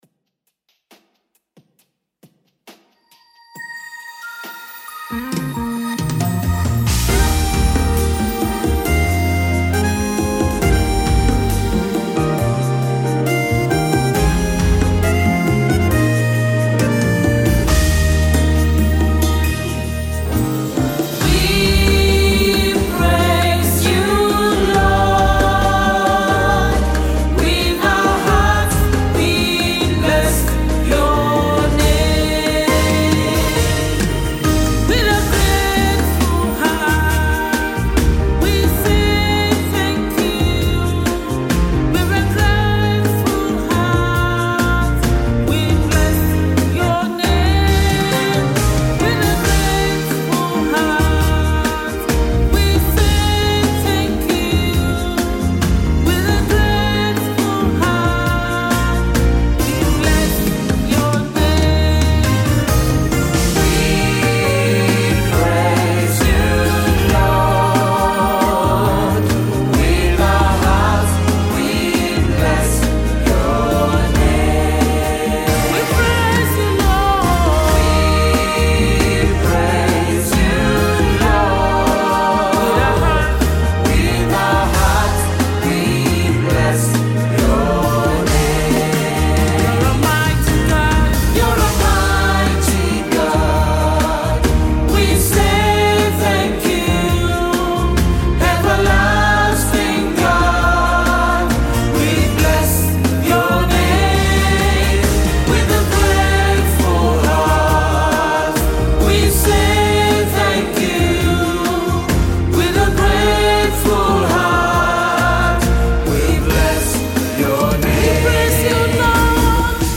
U.K based prolific gospel minister